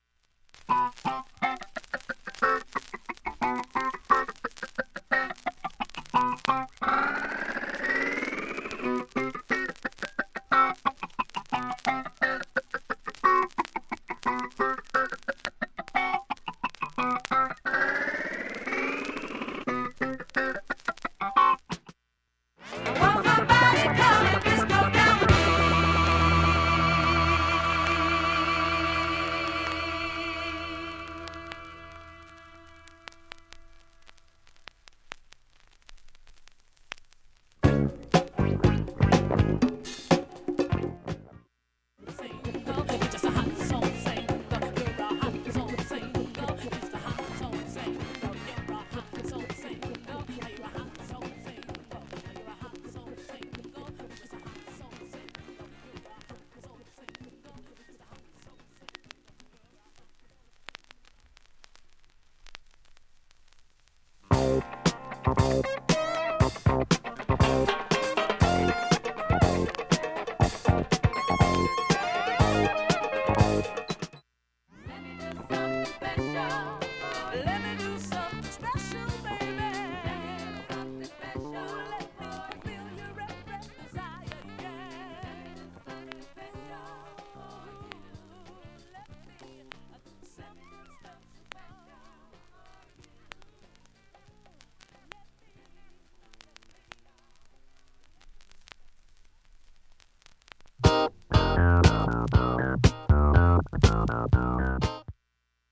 音質は良好です全曲試聴済み、
７回までのかすかなプツが２箇所
３回までのかすかなプツが６箇所
単発のかすかなプツが５箇所